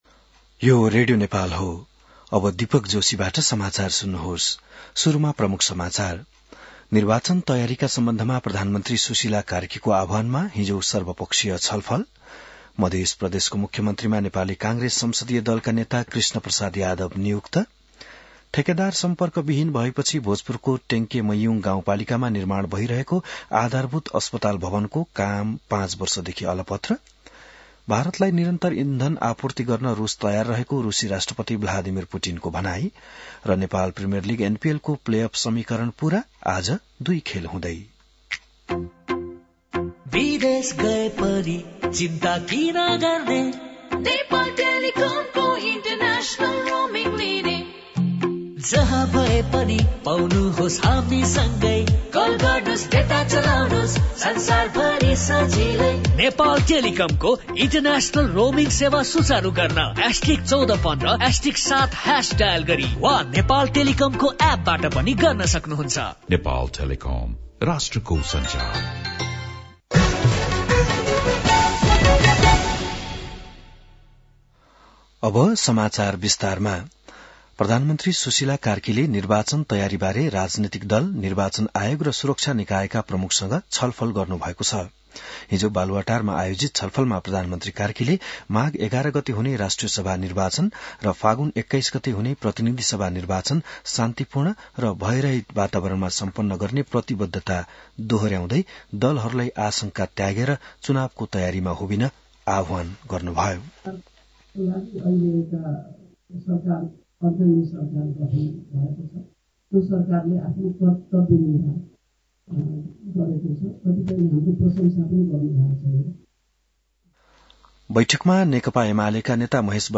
An online outlet of Nepal's national radio broadcaster
बिहान ७ बजेको नेपाली समाचार : २० मंसिर , २०८२